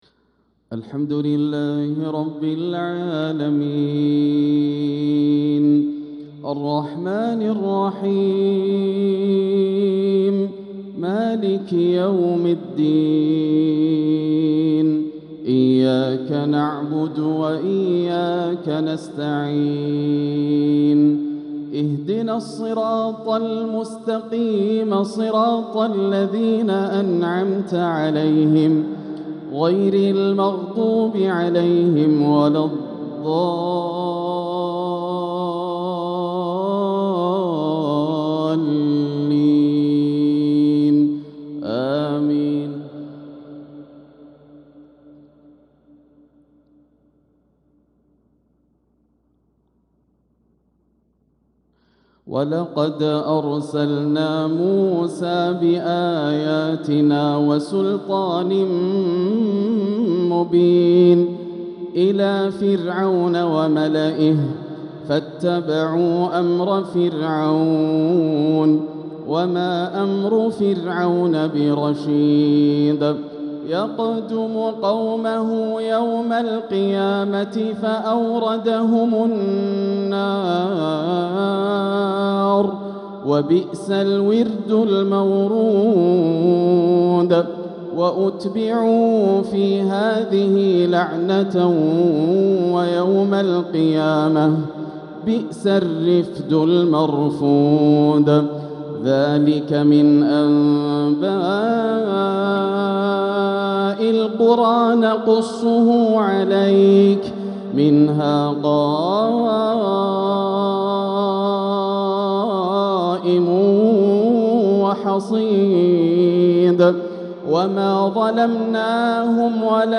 تلاوة تذيب لفائف الفؤاد للشيخ ياسر الدوسري من سورة هود (وكذلك أخذ ربك) ١٤ محرم ١٤٤٦ هـ > عام 1446 > الفروض - تلاوات ياسر الدوسري